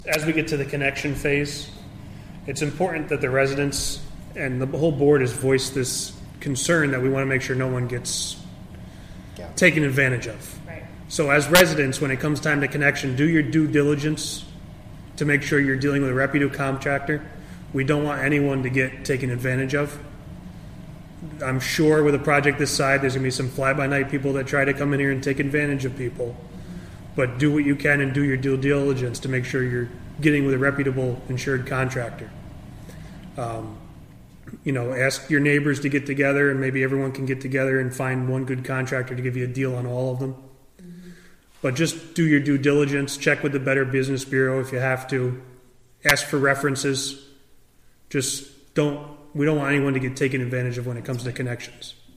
Catskill Town Hall